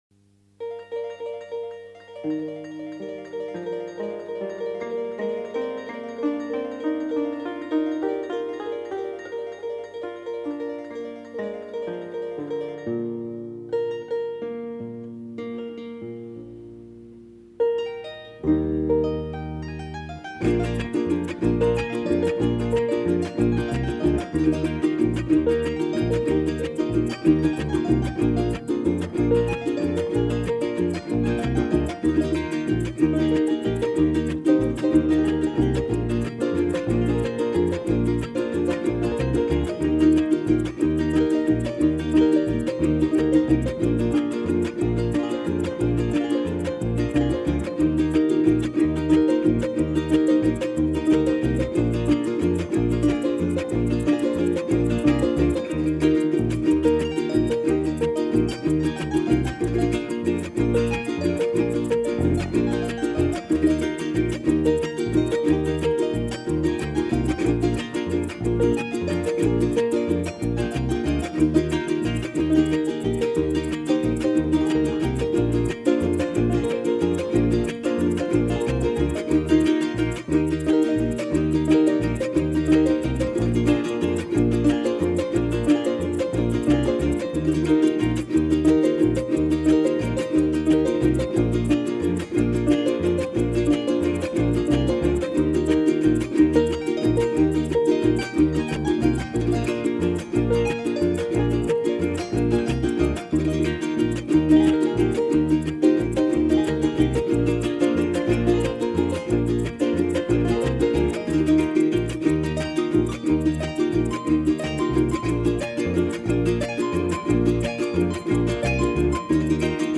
Internationale Folklore: